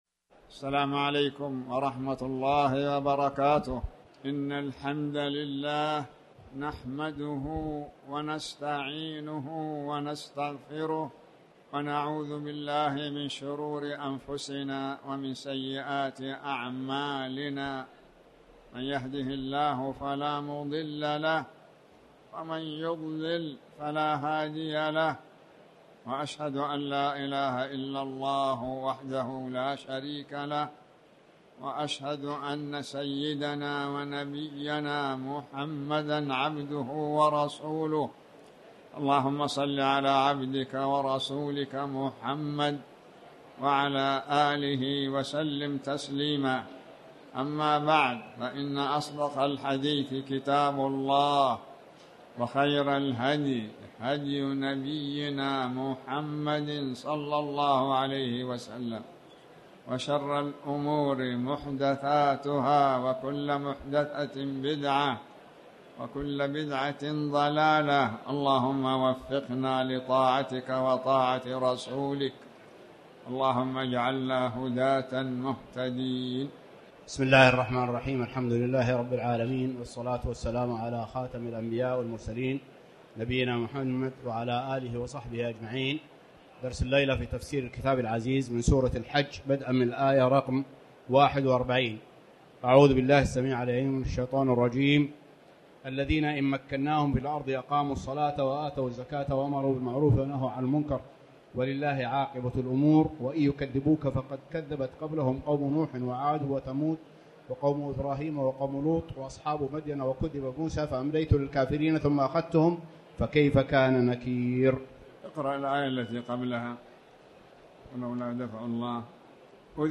تاريخ النشر ٢ رجب ١٤٣٩ هـ المكان: المسجد الحرام الشيخ